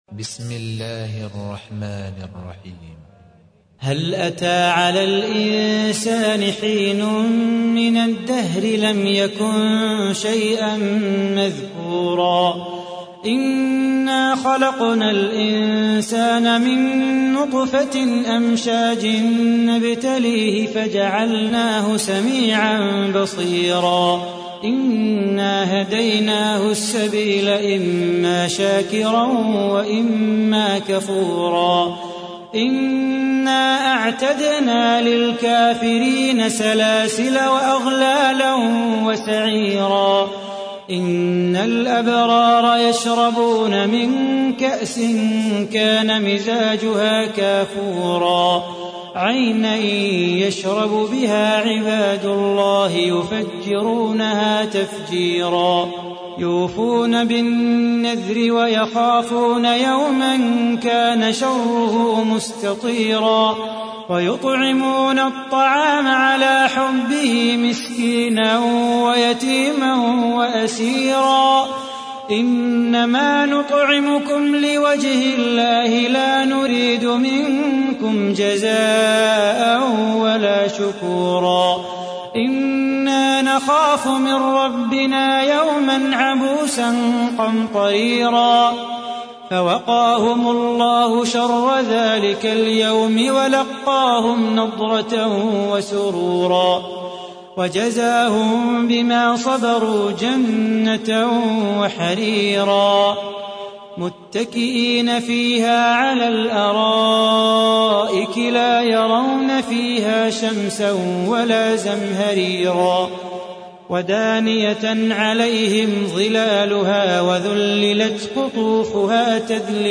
تحميل : 76. سورة الإنسان / القارئ صلاح بو خاطر / القرآن الكريم / موقع يا حسين